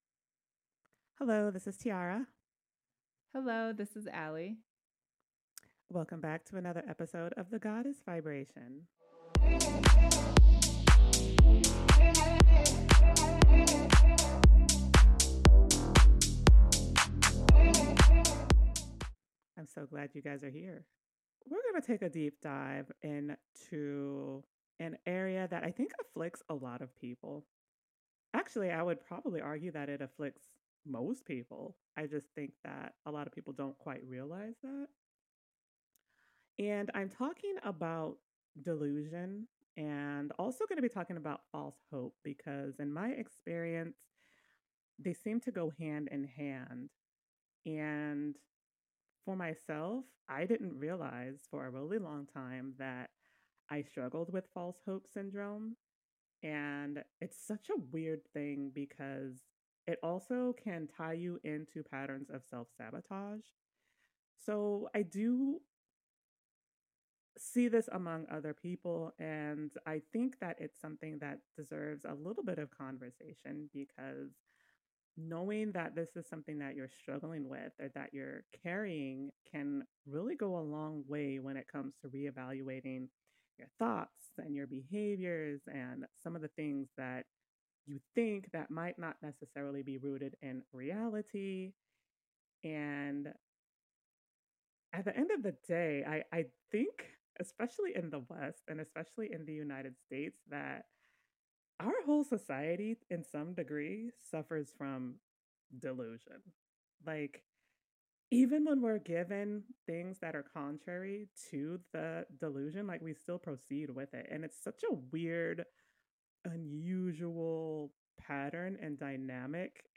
Two best friends share their experiences on life, love and mysticism on a journey of self improvement.